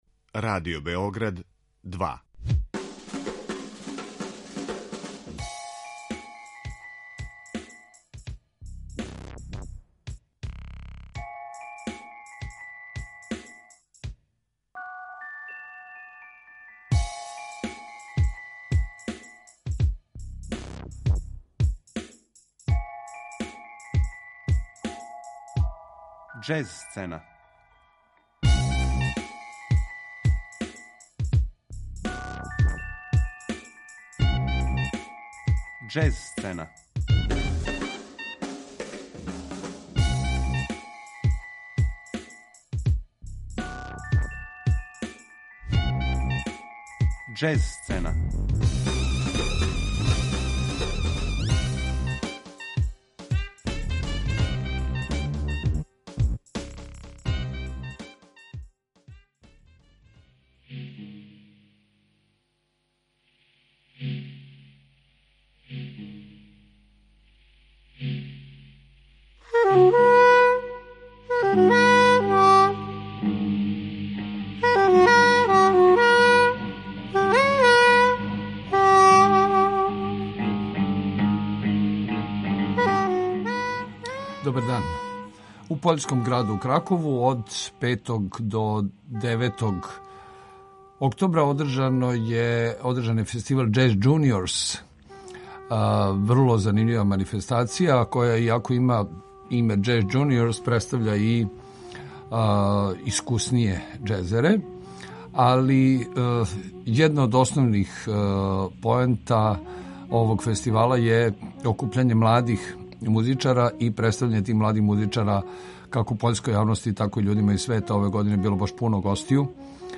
Џез сцена